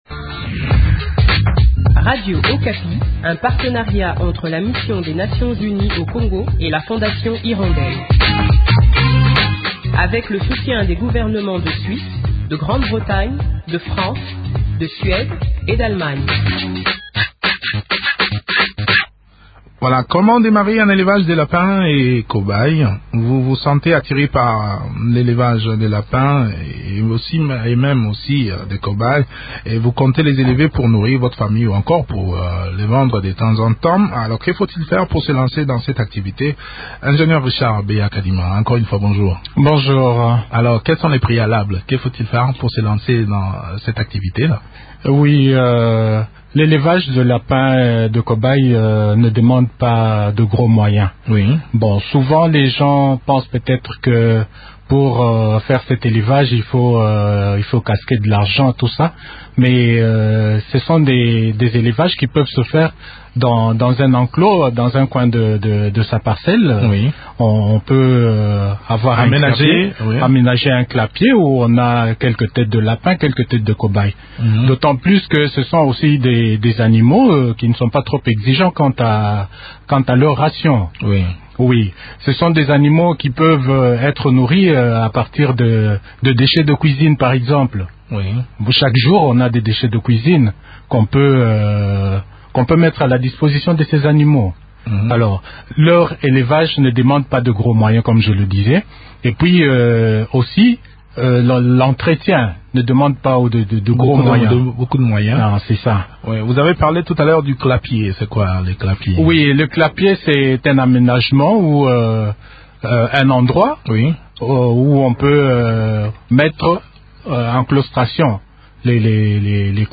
ingénieur zootechnicien.